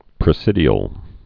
(prĭ-sĭdē-əl) also pre·sid·i·ar·y (-ĕrē)